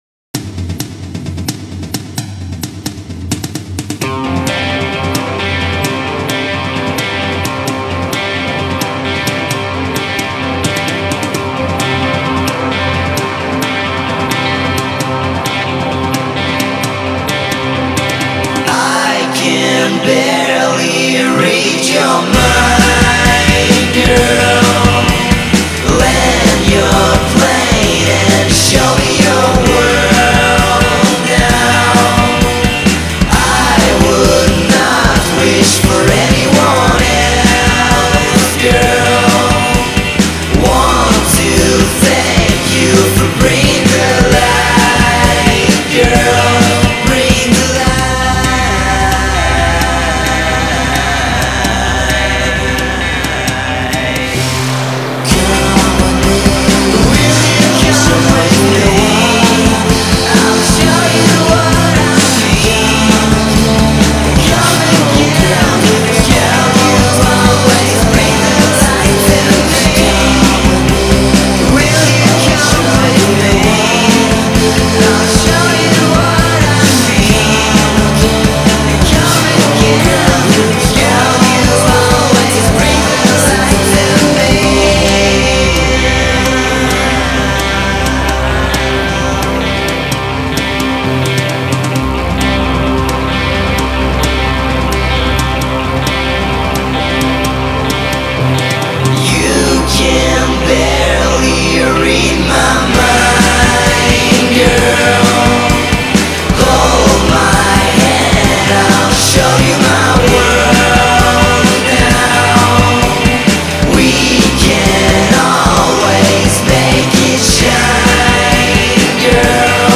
a collection of very professional sounding demos.